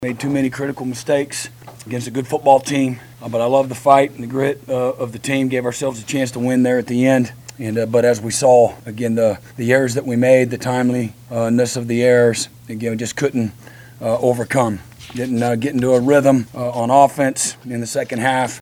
OU head coach Brent Venables talks about the contest postgame.